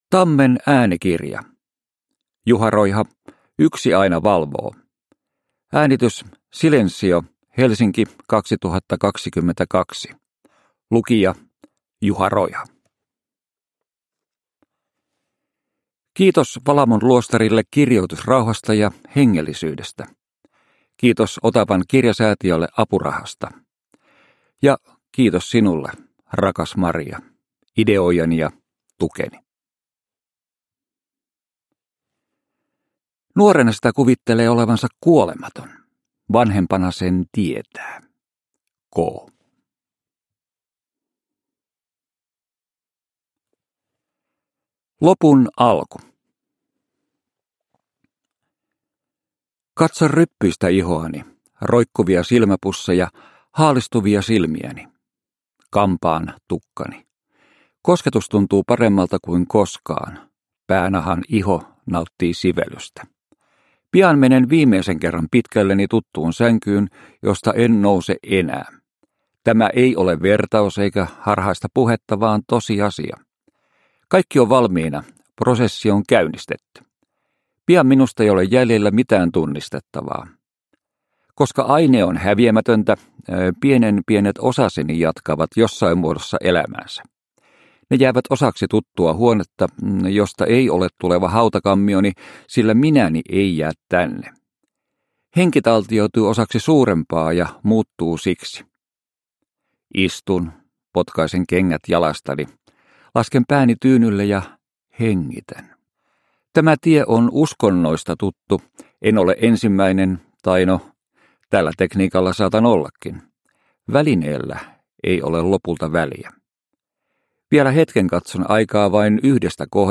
Yksi aina valvoo – Ljudbok